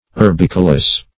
Urbicolous \Ur*bic"o*lous\, a.
urbicolous.mp3